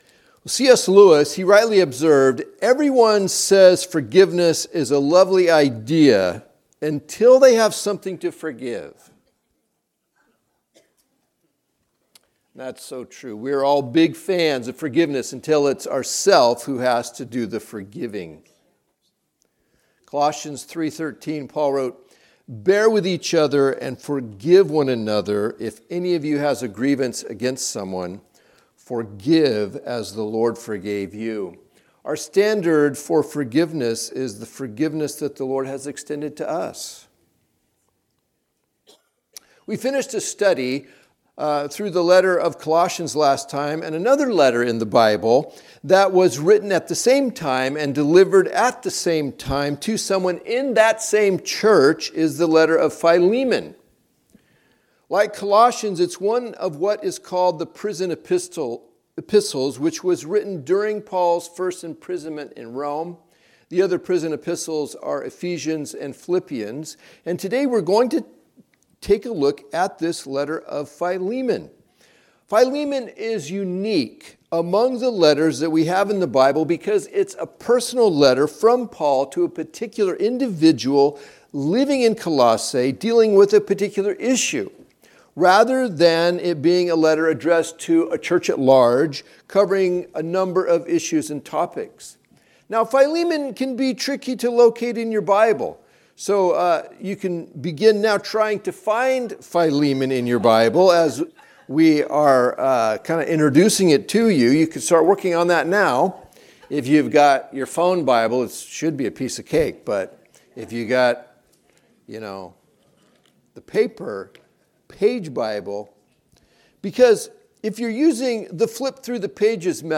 Sunday Morning Teachings